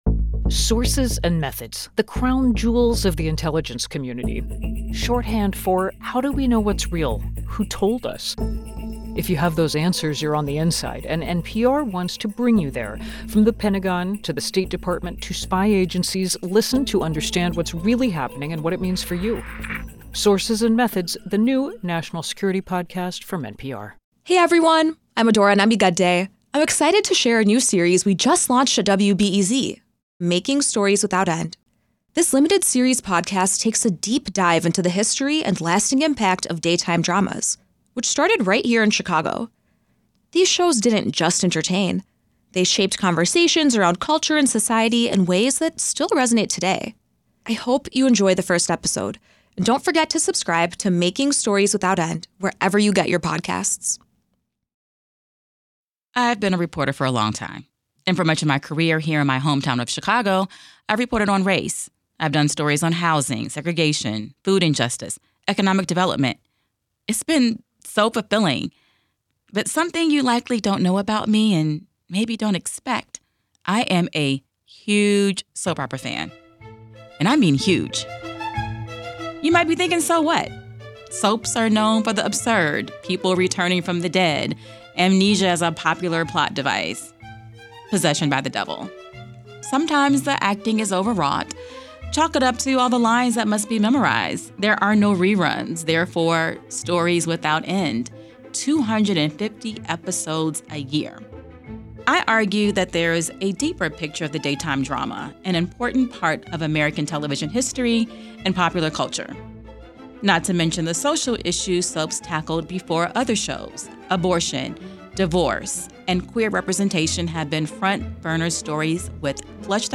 You’ll hear the story behind the stories from scholars, actors, writers – from the past and now – as well as fans.